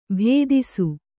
♪ bhēdisu